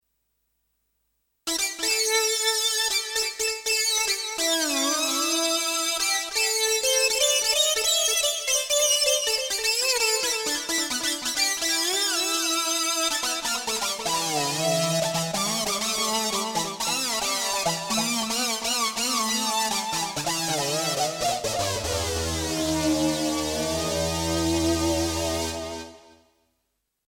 They all have been recorded without any external effekts.
Mean sync lead sound
OB12_Sync_Lead.mp3